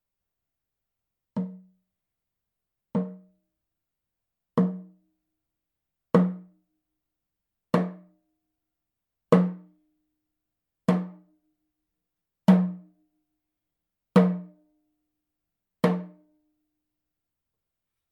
ネイティブ アメリカン（インディアン）ドラム NATIVE AMERICAN (INDIAN) DRUM 10インチ（elk アメリカアカシカ・ワピチ）
ネイティブアメリカン インディアン ドラムの音を聴く
乾いた張り気味の音です